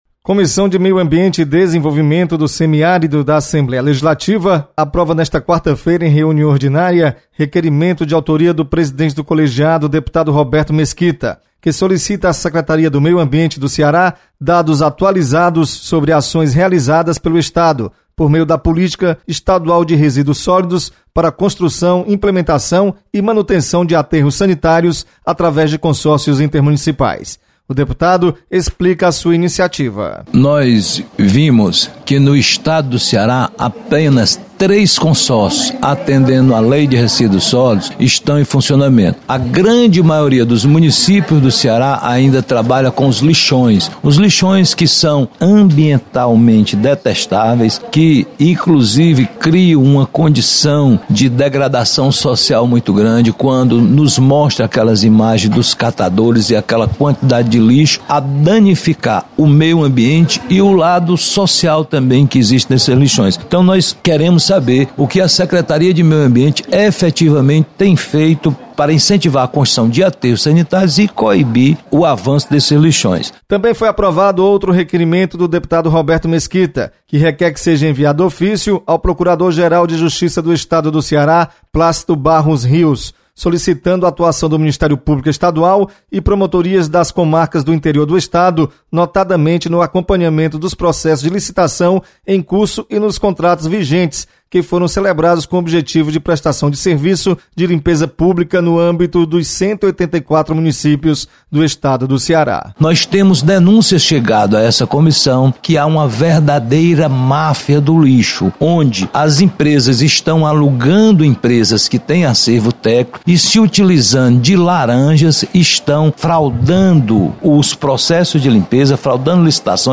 Comissão de Meio Ambiente e Desenvolvimento do Semiárido realiza reunião para debater a Política Estadual de Resíduos Sólidos. Repórter